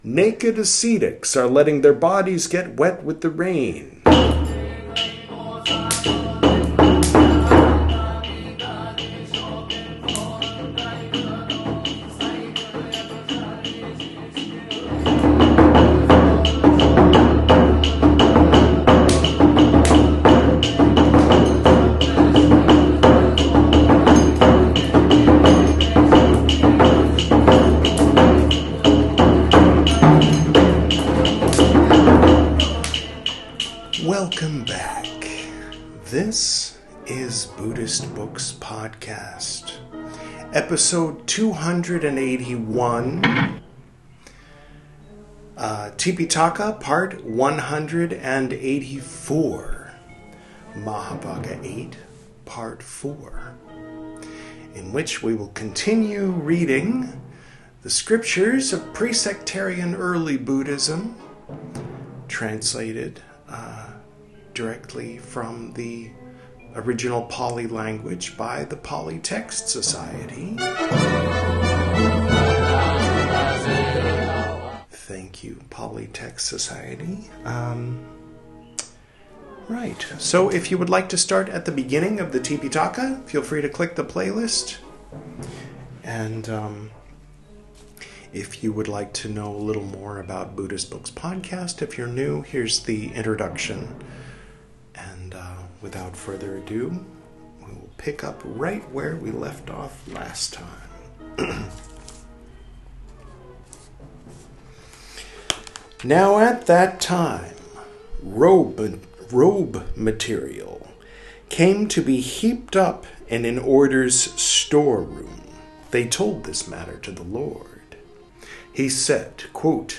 This is Part 184 of my recital of the 'Tipiṭaka,' the 'Three Baskets' of pre-sectarian Buddhism, as translated into English from the original Pali Language. In this episode, we'll continue reading 'Mahāvagga VIII,' from the 'Vinaya Piṭaka,' the first of the three 'Piṭaka,' or 'Baskets.'